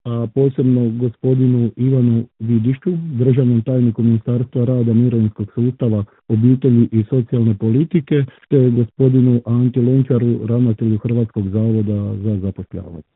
u razgovoru za Media servis